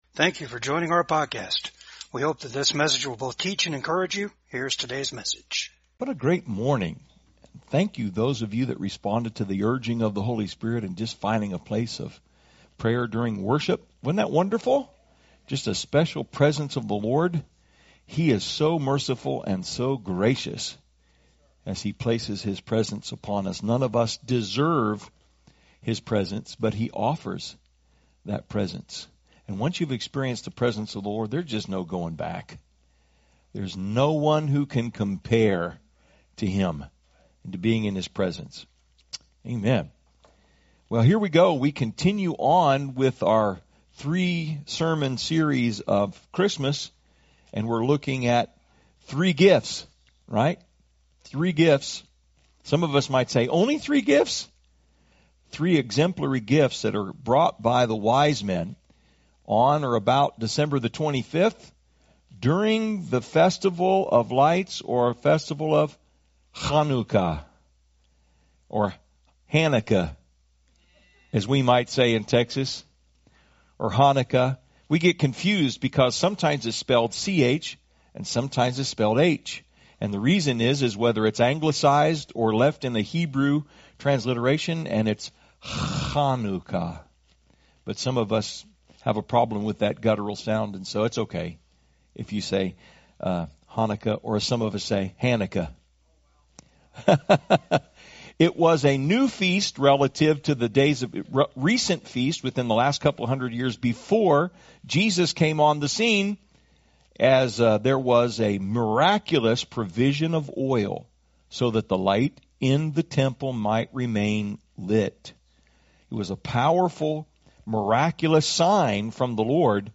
Service Type: VCAG SUNDAY SERVICE